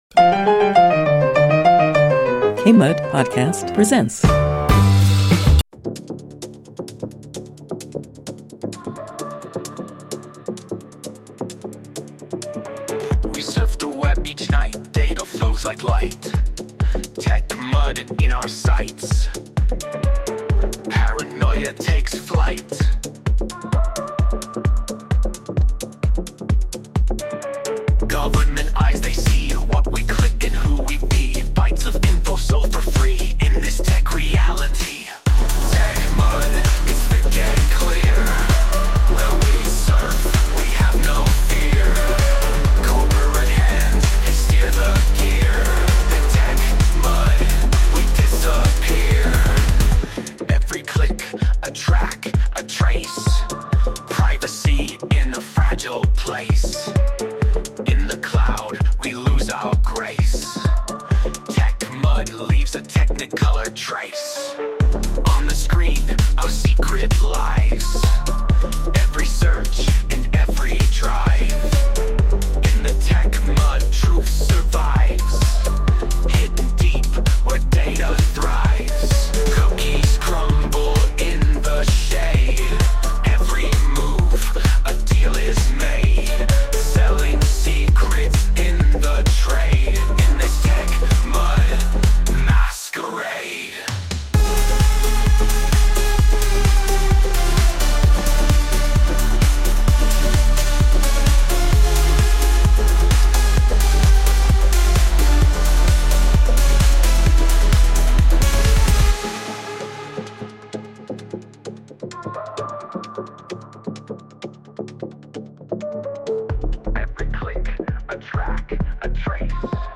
They also discuss the controversial new feature in Windows 11 that takes screenshots every few minutes. Tune in for these discussions and more, and hear from our on-air callers with their own tech questions and insights.